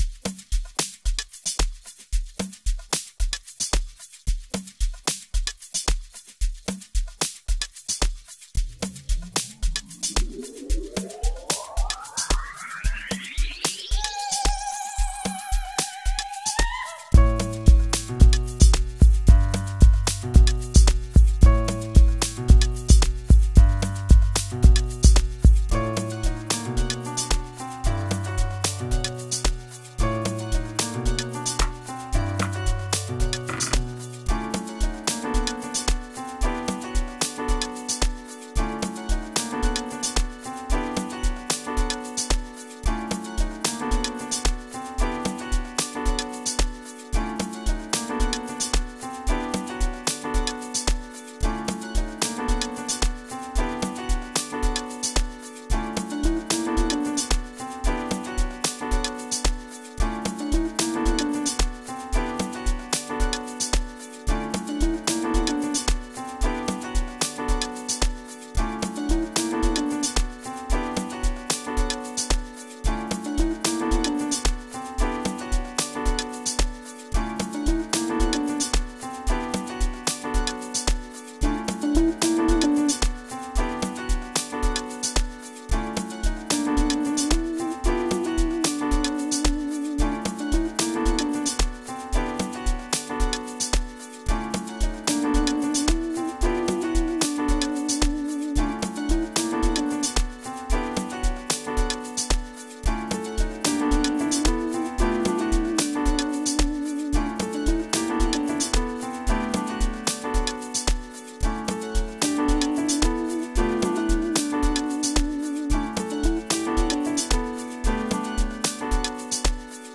04:43 Genre : Amapiano Size